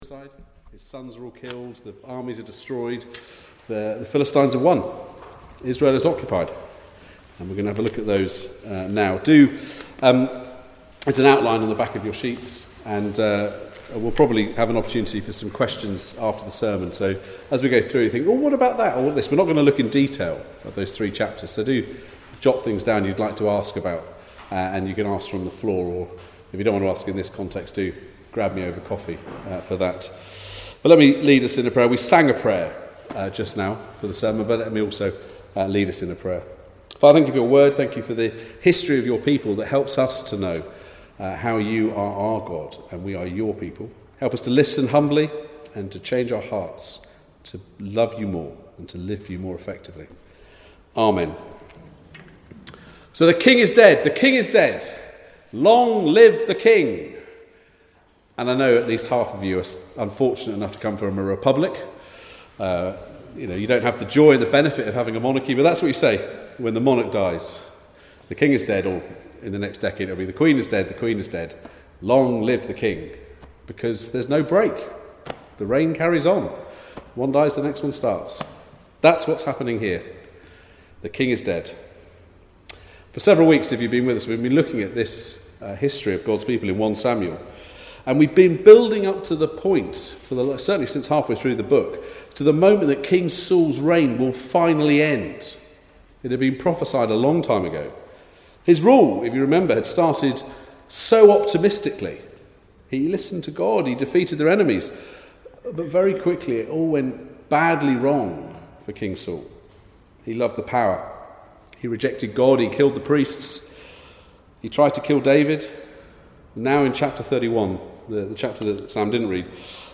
1 Samuel Passage: 1 Samuel 29-31 Service Type: Weekly Service at 4pm Bible Text